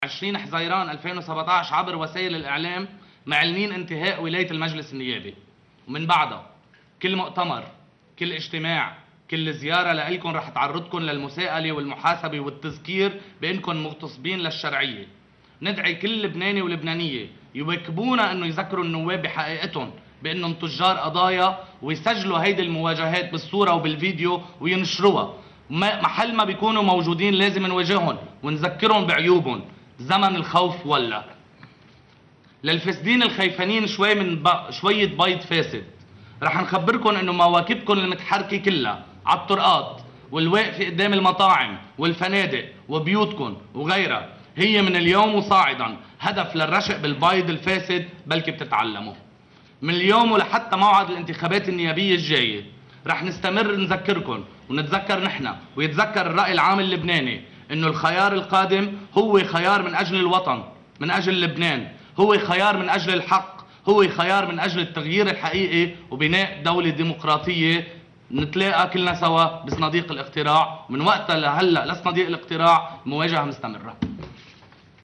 في مؤتمر صحفي للجمعية: